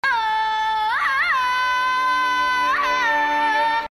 Young Tarzan’s Yell